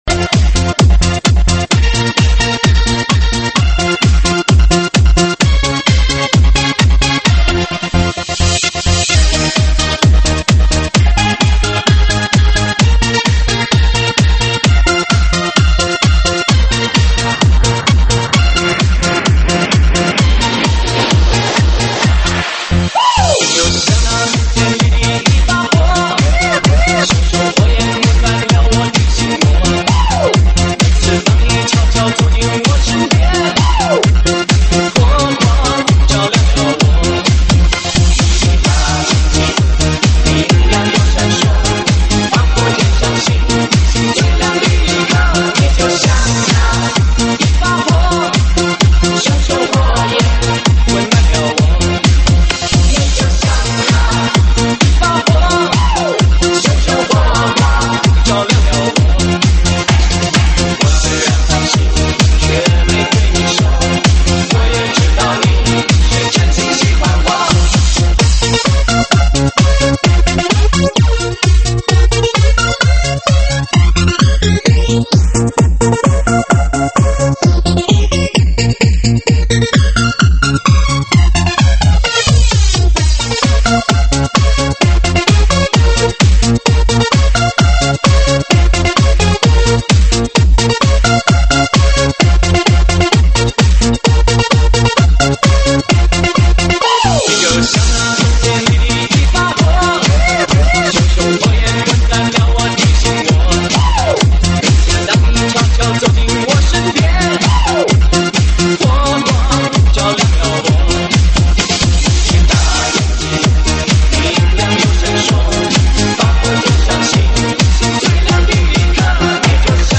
舞曲类别：中文舞曲